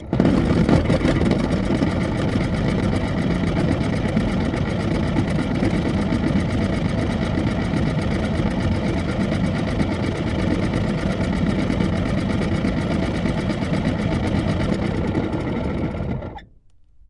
沃尔沃加热器 " 沃尔沃马达 5
描述：一辆老沃尔沃旅行车的加热器旋转起来，运行，然后停止。 它非常明显，而且，坏了。 2010年9月用Zoom H4录制的。 没有添加任何处理。
Tag: 加热器 电动机 旋转 沃尔沃 抱怨